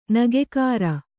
♪ nagekāra